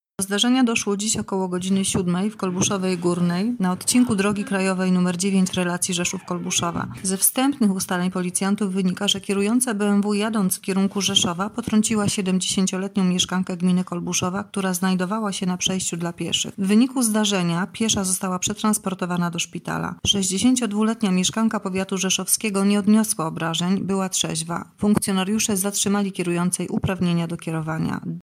Mówi oficer prasowy